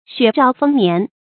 雪兆丰年 xuě zhào fēng nián 成语解释 谓冬天大雪是来年丰收的预兆。